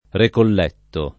[ rekoll $ tto ]